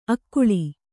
♪ akkuḷi